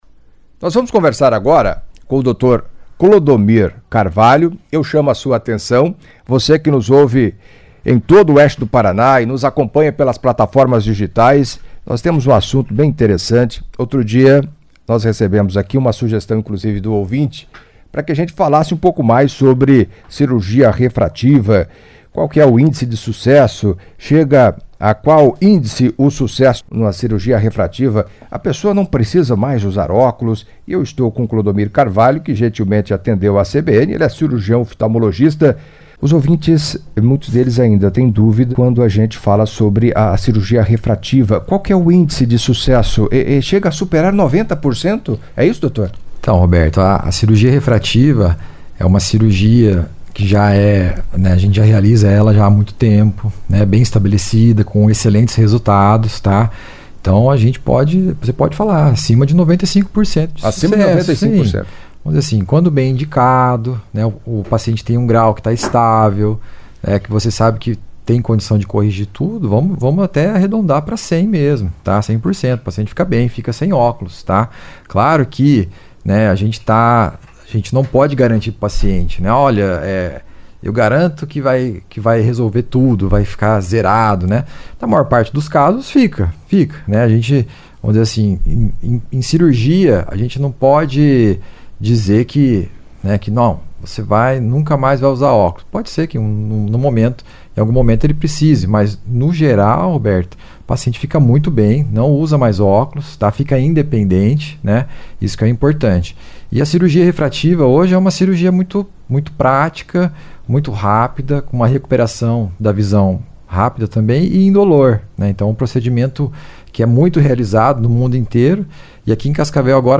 Em entrevista à CBN Cascavel nesta segunda-feira (01)